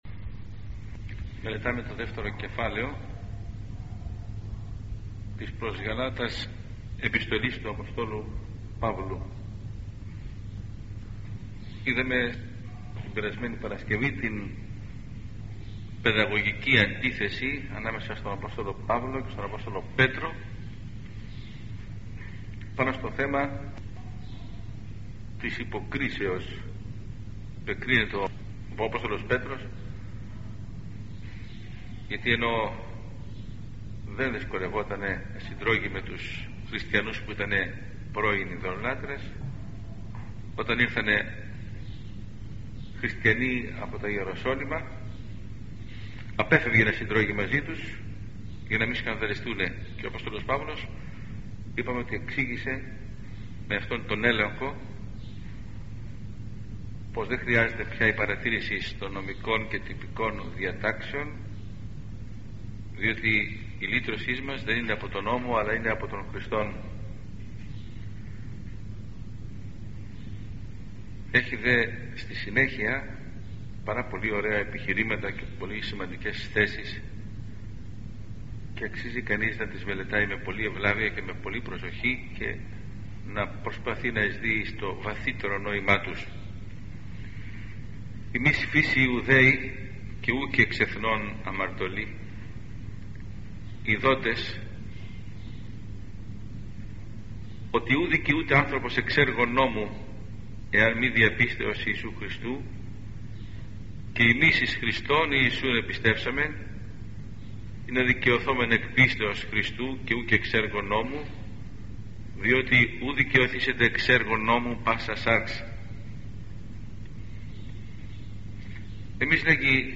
Ἑσπερ. κήρυγ.(Ἱ.Ν. Ταξιαρχῶν Ἐρμιόνη)